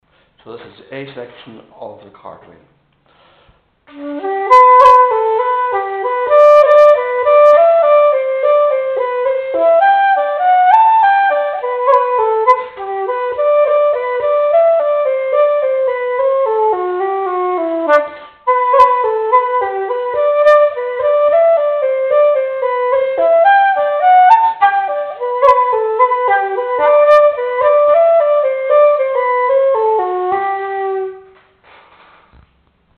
Part A on Flute
teaching part A of this jig on Flute